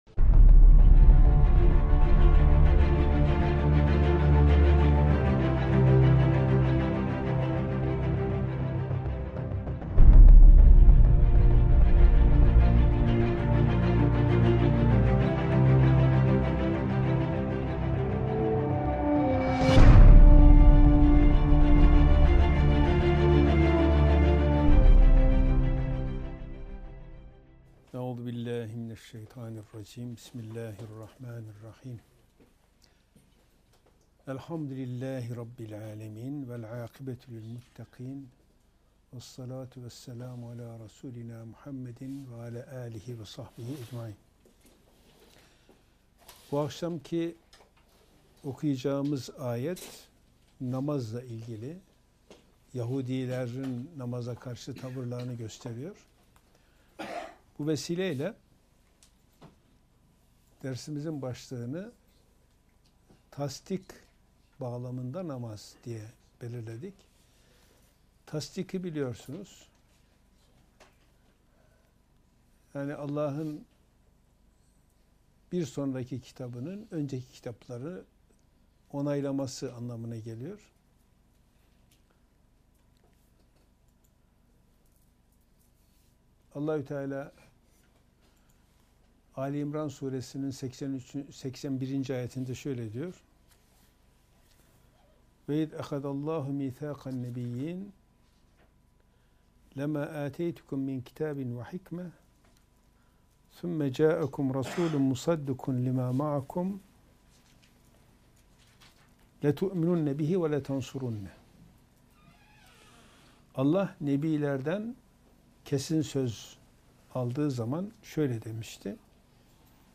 Gösterim: 716 görüntülenme Kur'an Sohbetleri Etiketleri: kuran > Maide 55.